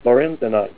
Help on Name Pronunciation: Name Pronunciation: Lorenzenite + Pronunciation
Say LORENZENITE Help on Synonym: Synonym: Ramsayite